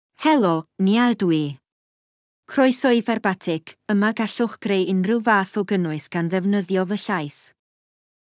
NiaFemale Welsh AI voice
Nia is a female AI voice for Welsh (United Kingdom).
Voice sample
Listen to Nia's female Welsh voice.
Female